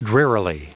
Prononciation du mot drearily en anglais (fichier audio)
Prononciation du mot : drearily